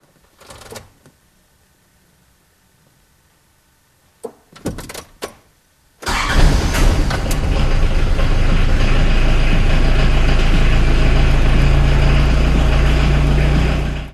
NISSAN Sunny N13 Diesel LX - Geräusche
Beschreibung: 00:01 - Zündschlüssel
00:03 - Vorglührelais
00:06 - Anlasser
Mikrofon: Rückbank bei geöffnetem Seitenfenster